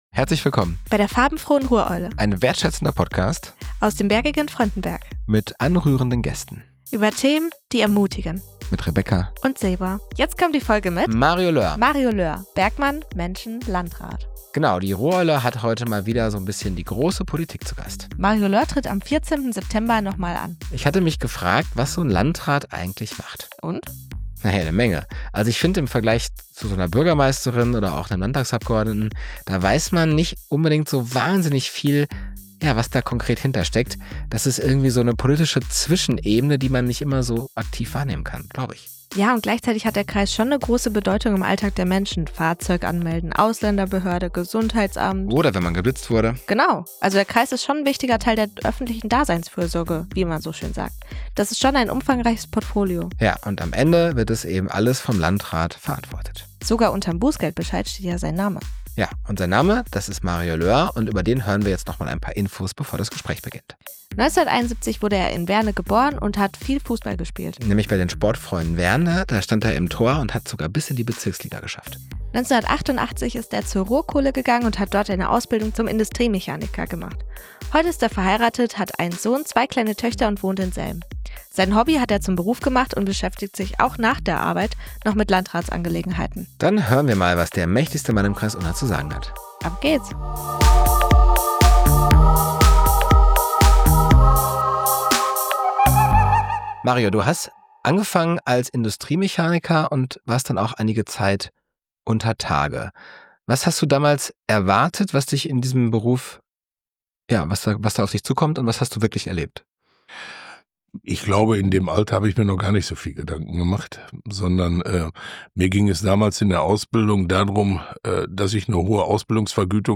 sprechen mit Landrat Mario Löhr über seinen Werdegang in der Kommunalpolitik. Löhr hebt die Bedeutung von Kameradschaft und Krisenmanagement hervor und fordert die Zuhörer zu aktiver Teilnahme auf.